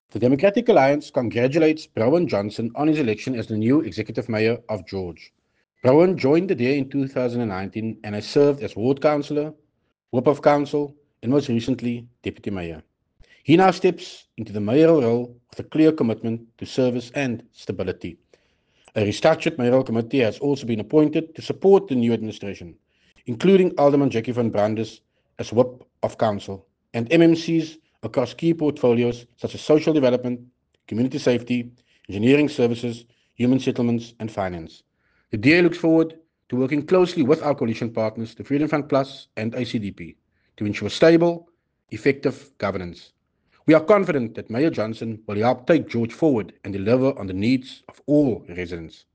soundbite by Tertuis Simmers